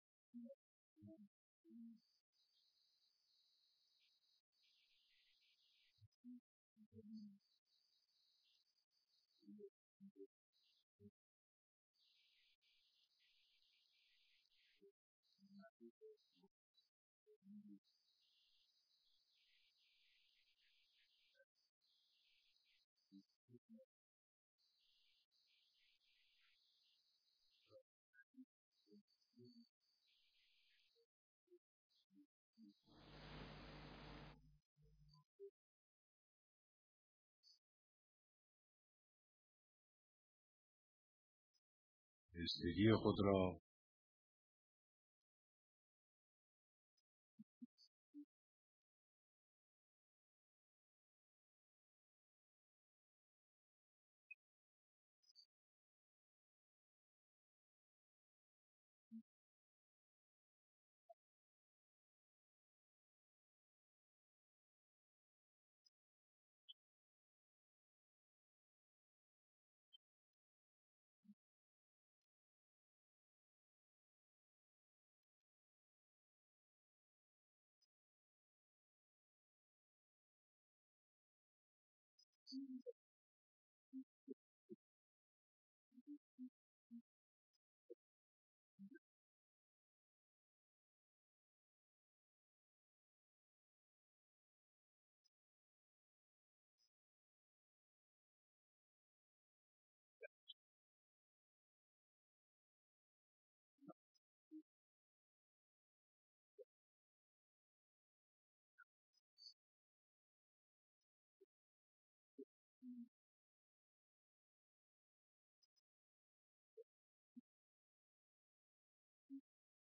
بیانات در ديدار مسئولان، سفرای كشورهای اسلامی و جمعی از اقشار مختلف مردم